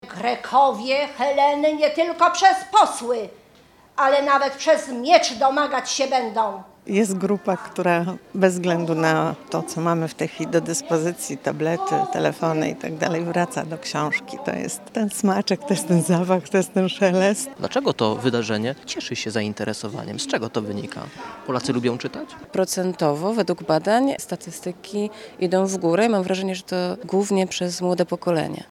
Z uczestnikami wydarzenia rozmawiał nasz reporter.